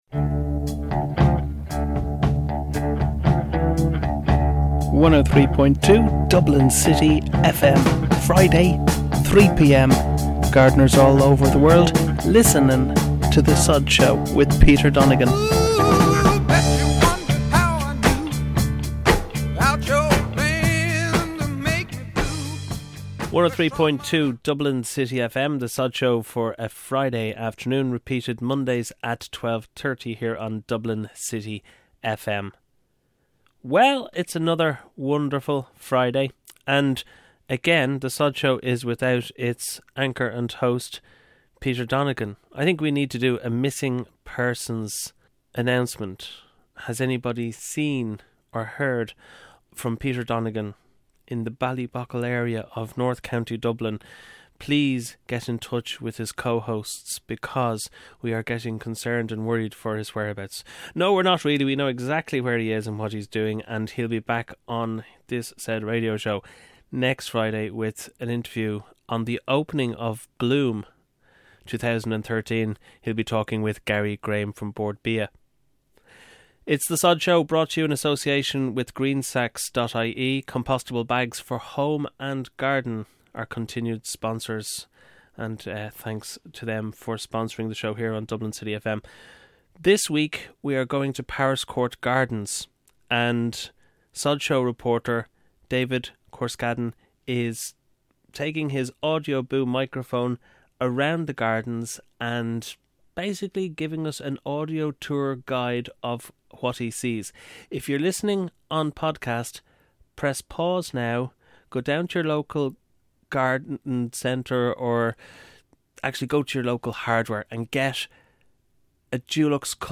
All that, so much more only on Ireland’s only [and award winning] garden radio show, The Sodshow.